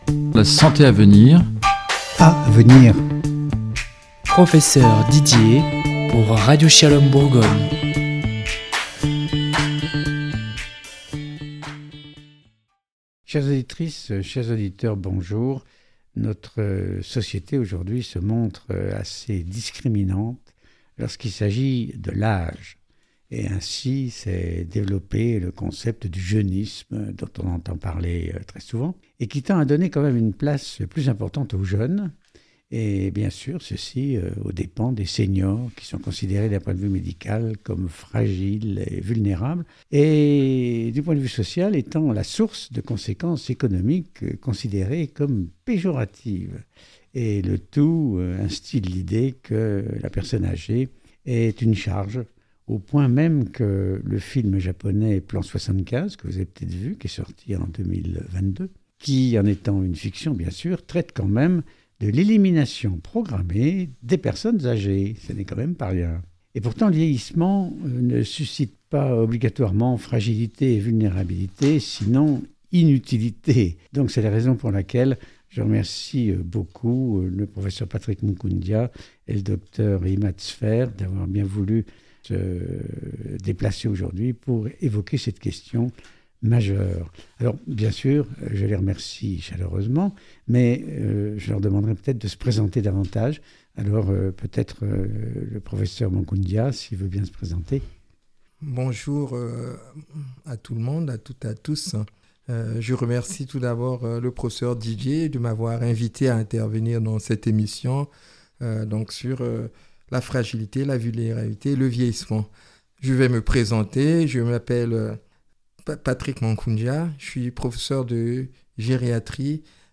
Cette discussion a donné lieu à une émission riche d’enseignementsnotamment quant aux possibilités offertes à travers diverses associations,capables de donner une image positive et dynamique des personnesâgées tout en amélioreront leur participation sociale et leur bien-être.